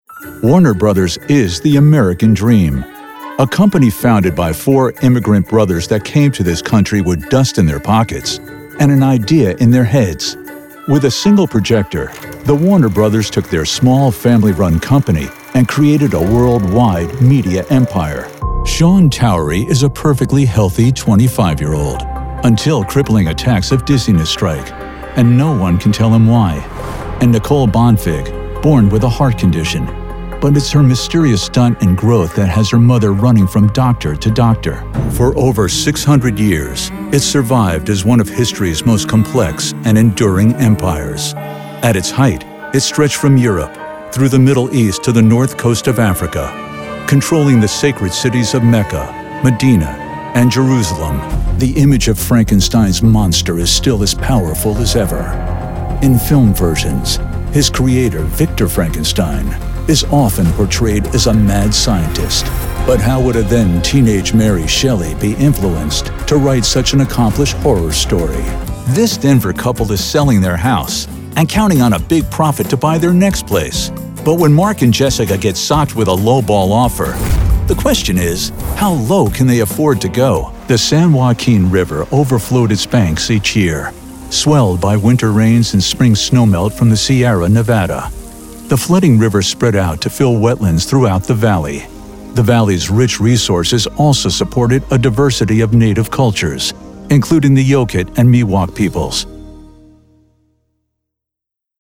Broadcast Narration
English - USA and Canada
Middle Aged
Broadcast Narration Demo.mp3